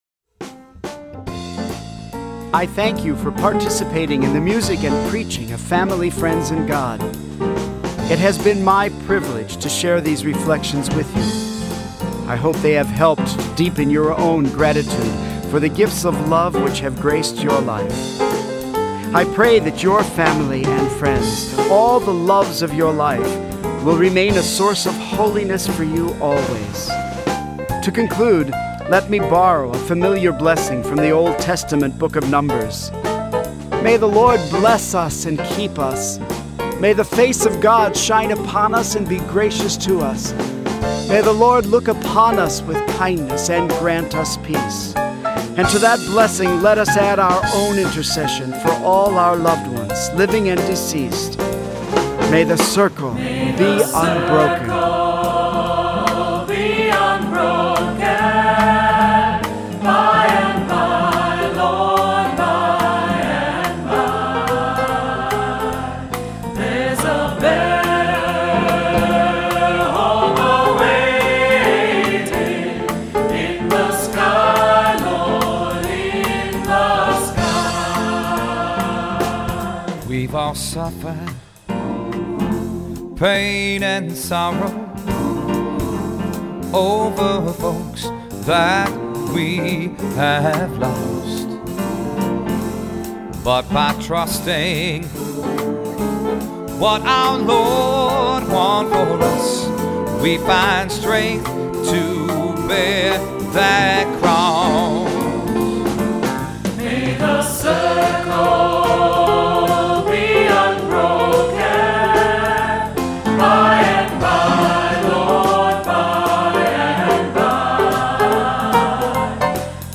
Voicing: Cantor, assembly, descant,SATB